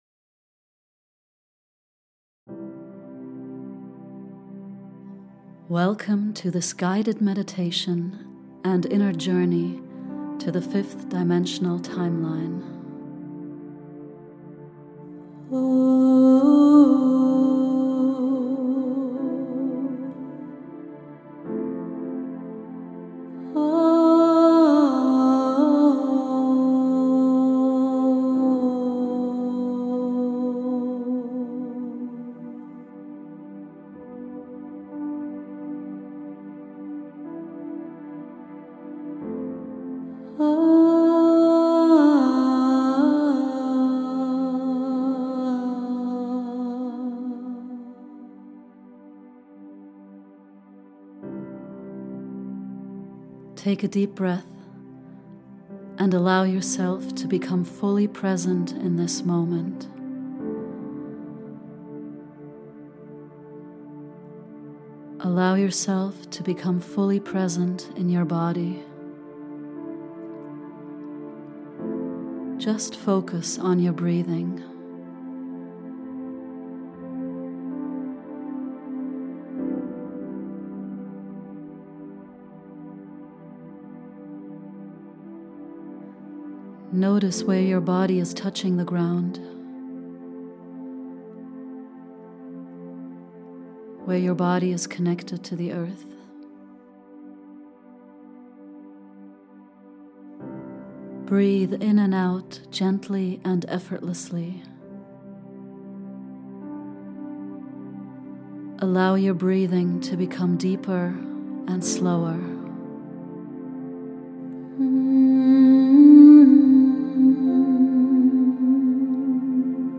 5d-timeline-meditation